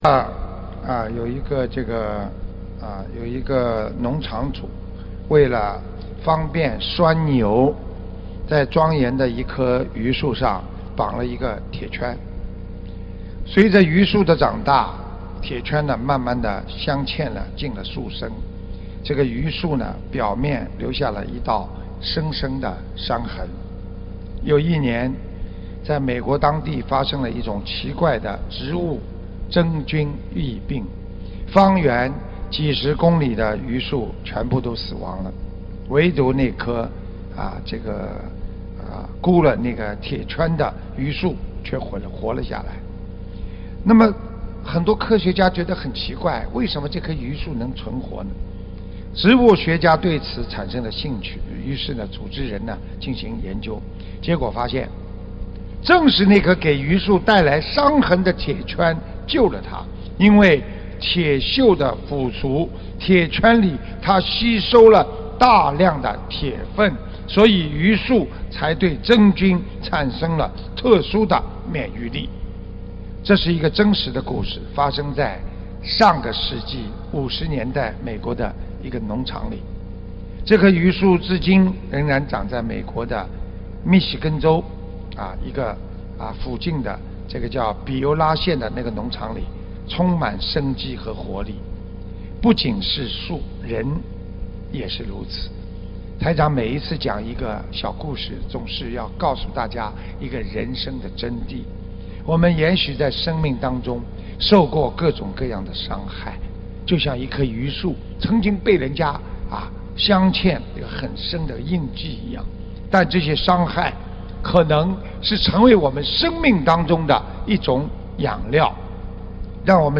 目录：法会开示_集锦